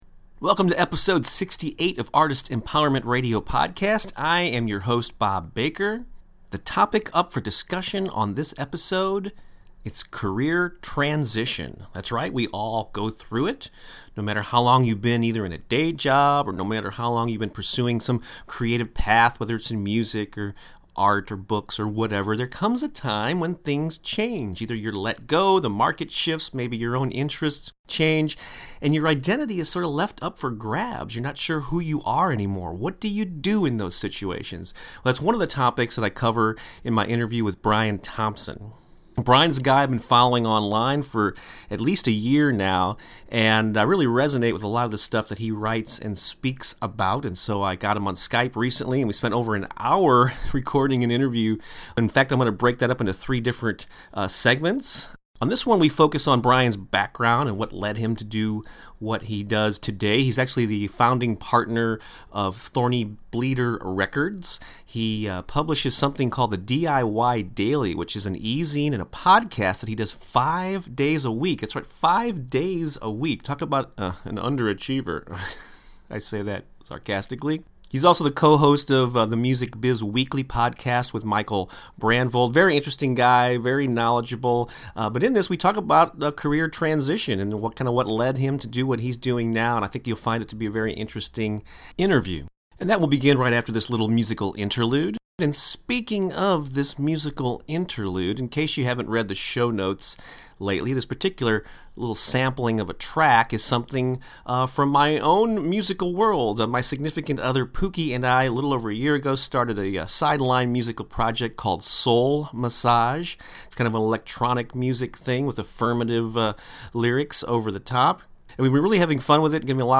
In this first of a three-part interview